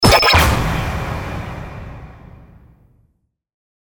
FX-100-IMPACT
FX-100-IMPACT.mp3